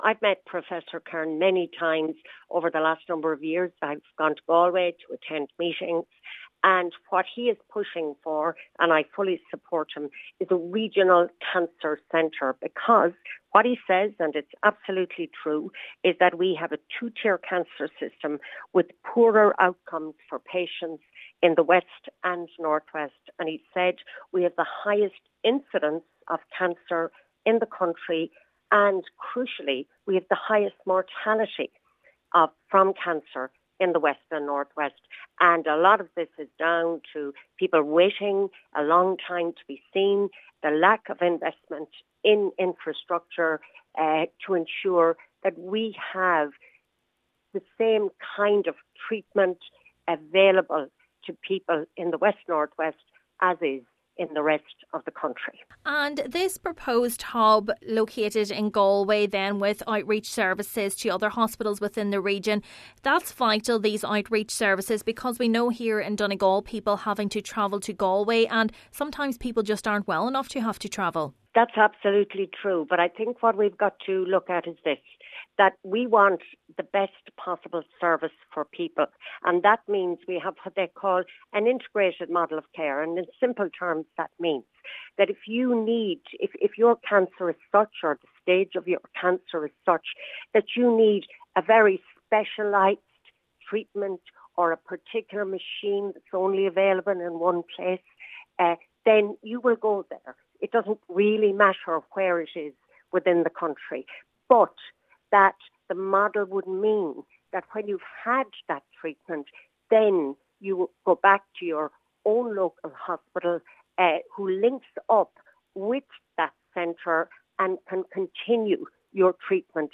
This is something South Donegal Deputy Marian Harkin believes needs to be expedited: